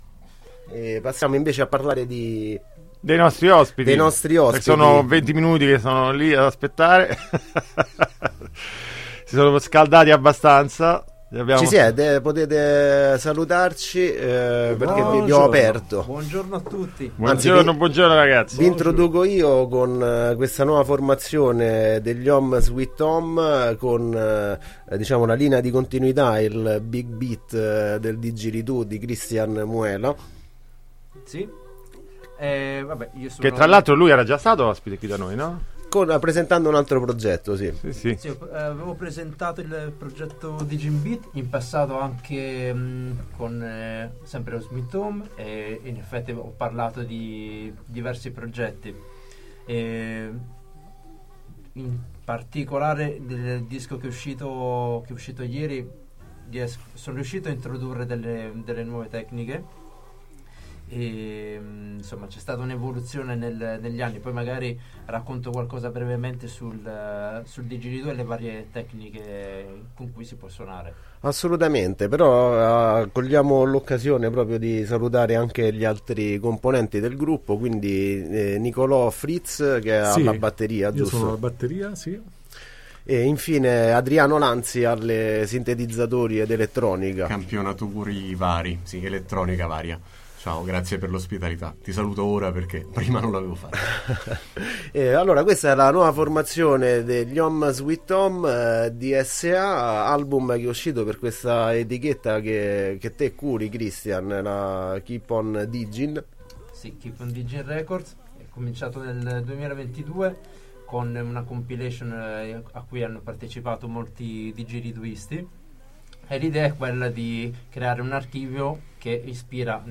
Intervista in studio con gli Ohm Sweet Ohm